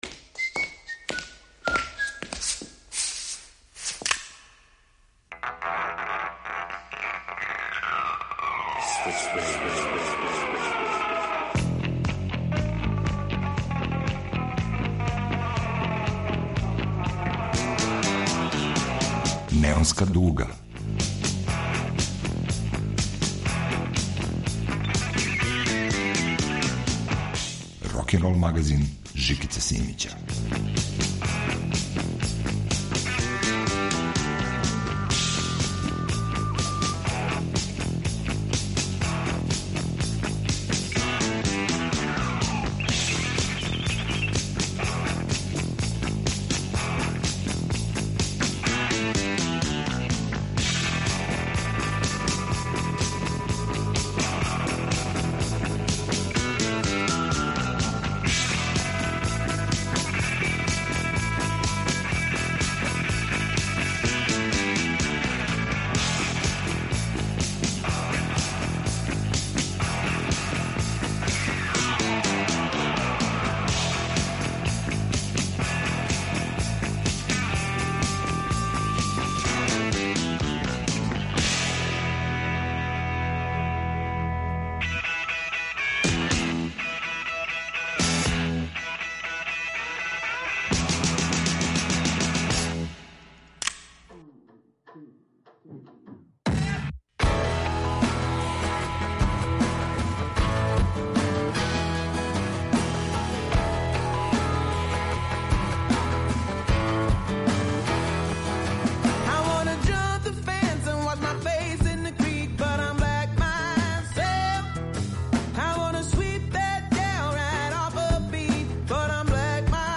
Неонска дуга емитује 22 песме.
Рокенрол као музички скор за живот на дивљој страни.
Помешане су песме из текуће продукције и оне из славне историје рок музике. Разни жанрови, традиционално и модерно „руку под руку".